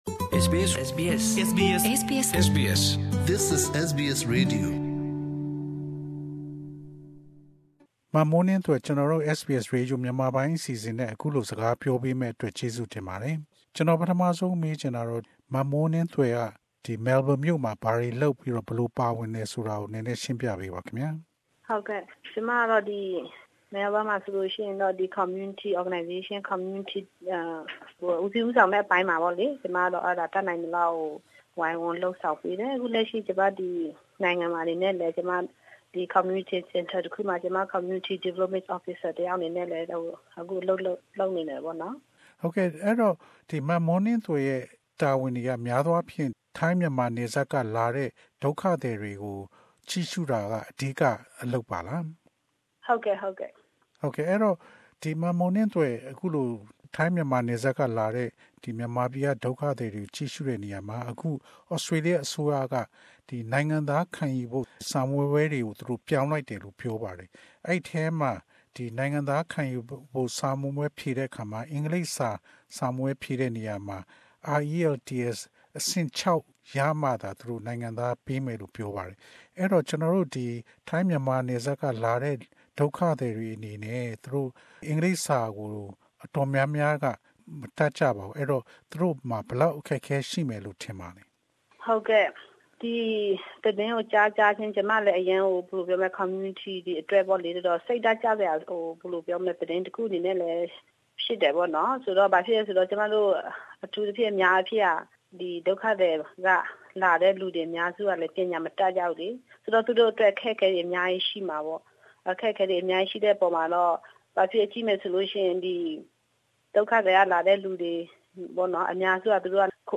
Interview two Burmese community workers on citizenship test changes.